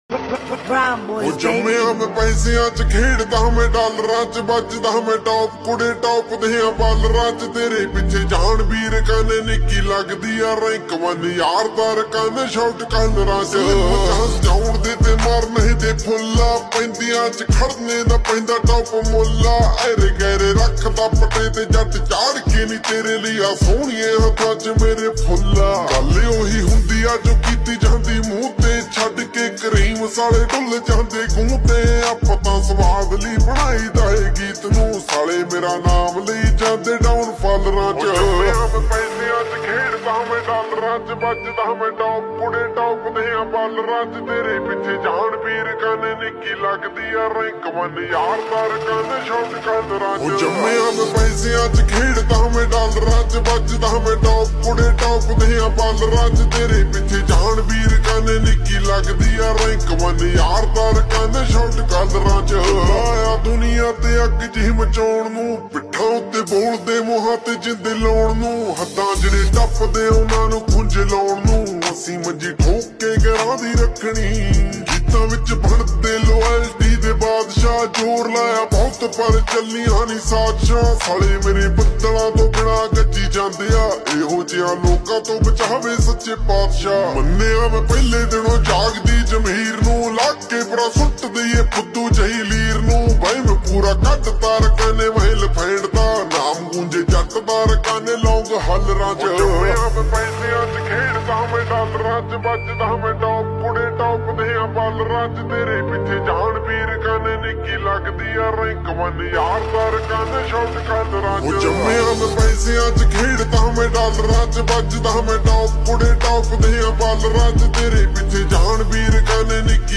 SLOWED+REWERB